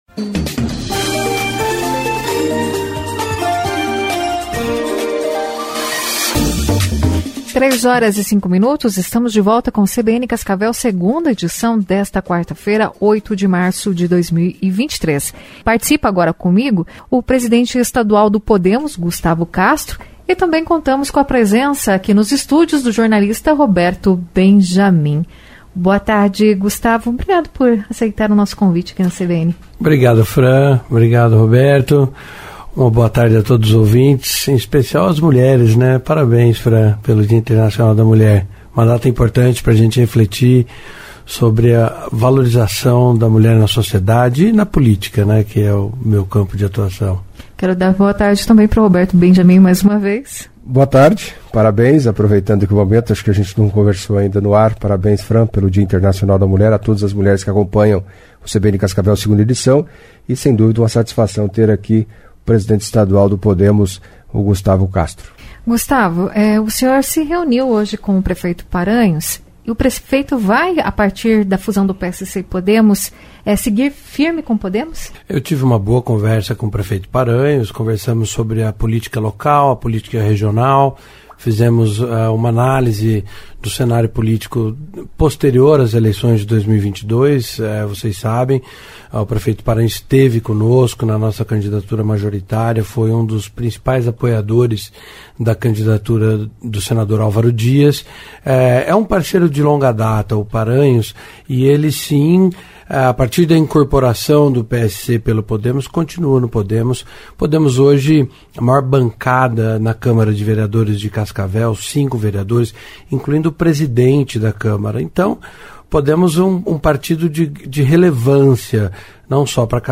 Em entrevista à CBN Cascavel nesta quarta-feira (08)